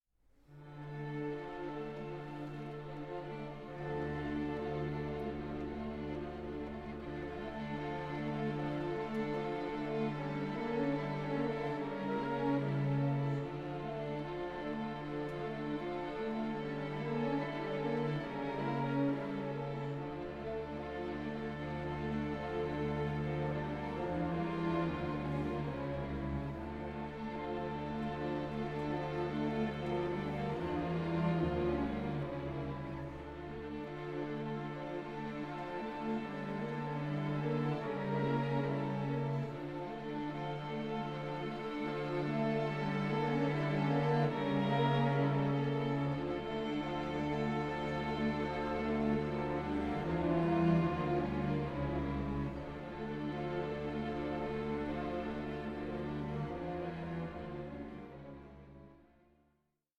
string orchestra arrangement